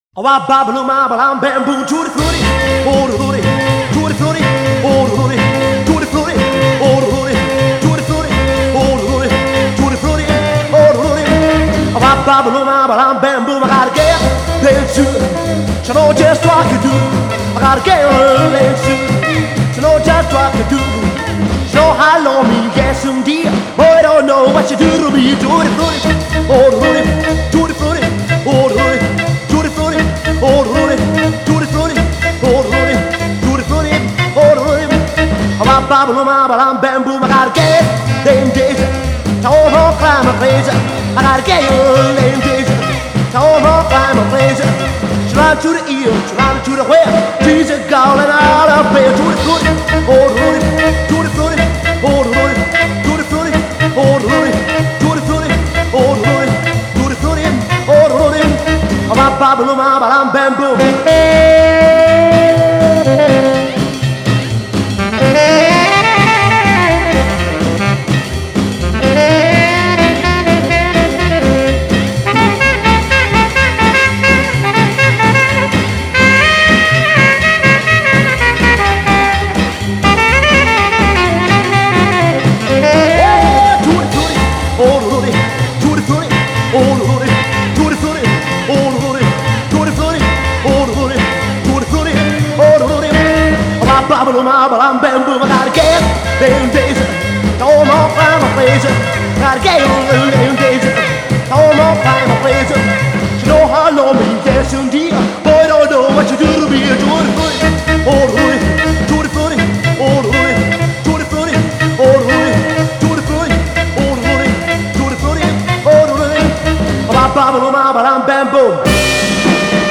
Genre: Pop, Jazz, Rock'n'roll